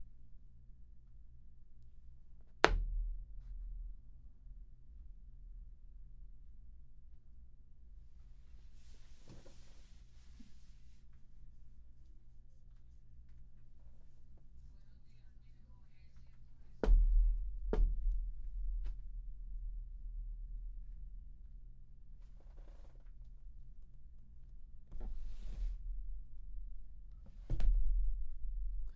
描述：砰的一声
Tag: 砰的一声 墙壁